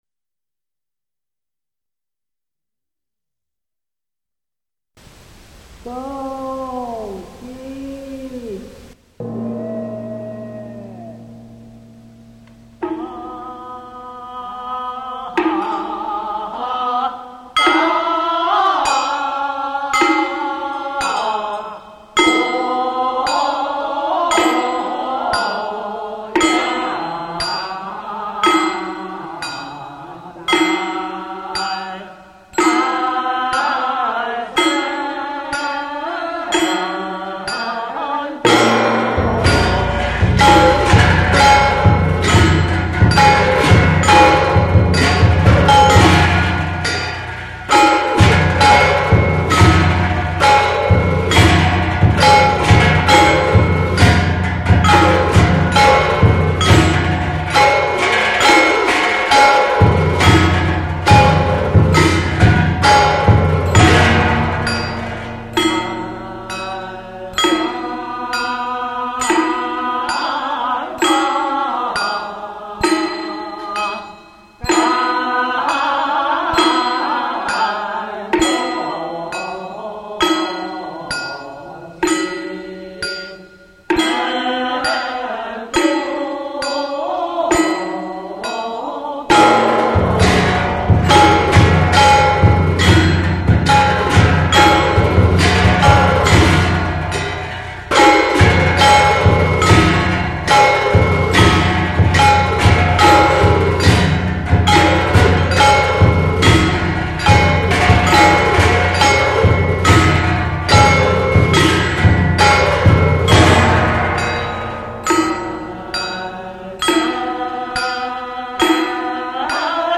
四川道教音乐—洞经古乐10
洞经古乐自明清以来即风行于中国各地文化阶层，因其主要用于谈演道教经典《玉清无极总真文昌大洞仙经》（简称洞经）而得名，集汉族民间小调、道教音乐、佛教音乐和宫廷音乐之大成，是明清时期传入丽江的道教古乐，带有汉曲丝竹乐风。